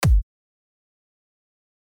Eine Kick.